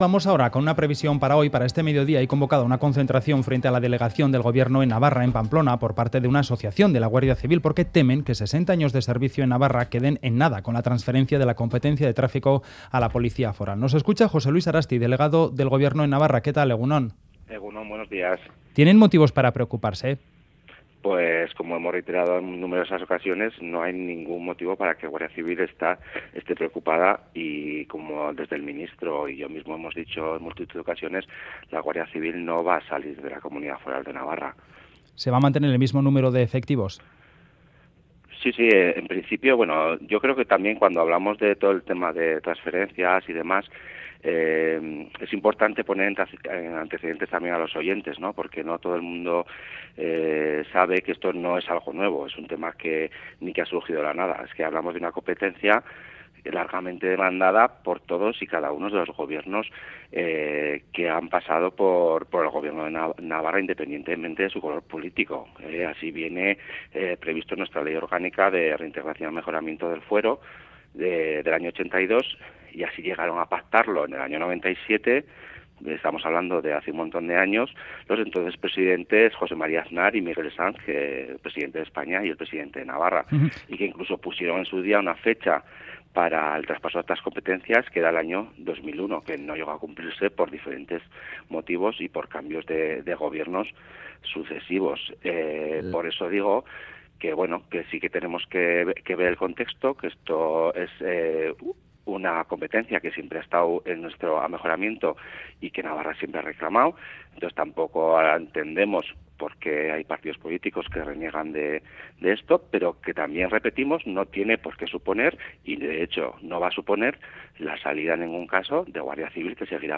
Audio: Entrevistamos a José Luis Arasti, delegado del Gobierno en Navarra. Hablamos sobre el acuerdo para el traspaso de la competencia de tráfico.